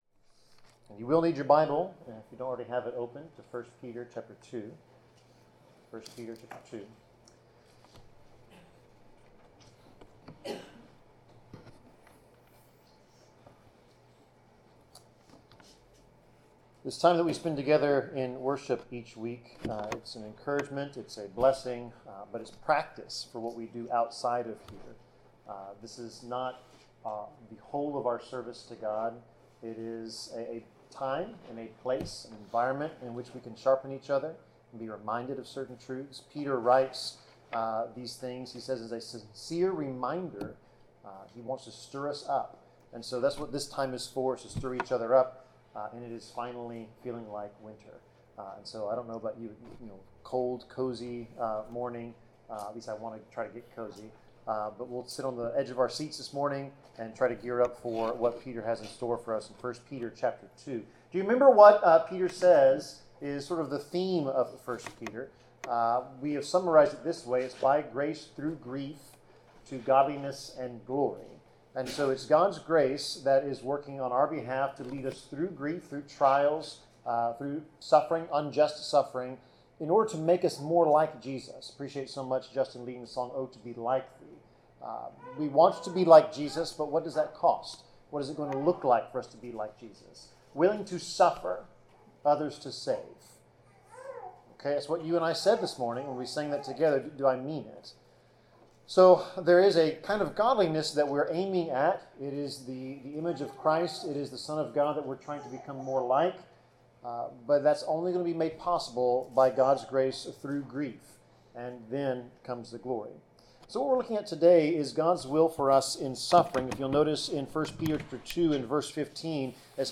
Passage: 1 Peter 2:19-25 Service Type: Sermon